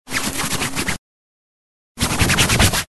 Звуки ткани
Звук попытки оттереть пятно с одежды рукой или сухой тряпкой